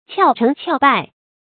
俏成俏敗 注音： ㄑㄧㄠˋ ㄔㄥˊ ㄑㄧㄠˋ ㄅㄞˋ 讀音讀法： 意思解釋： 近似于成或敗，謂非真成真敗。